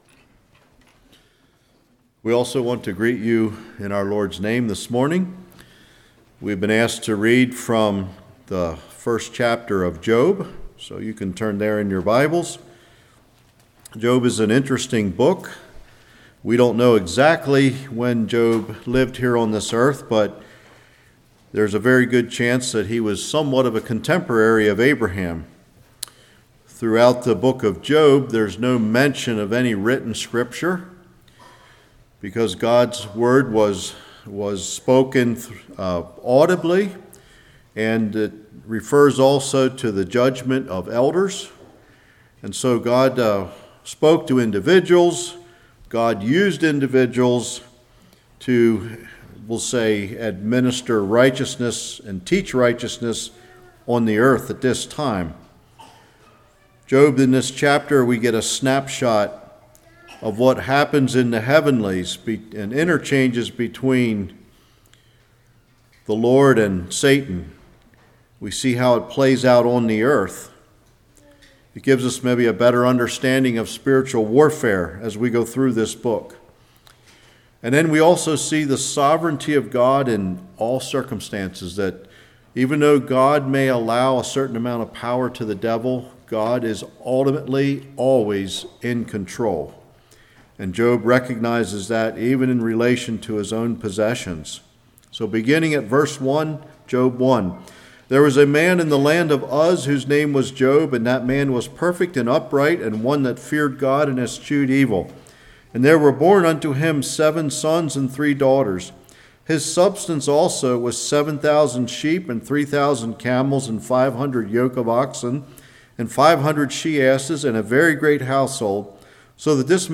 Job 1:1-22 Service Type: Morning Job’s Confidence